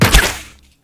Crossbow_LaunchArrow 01.wav